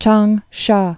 (chängshä)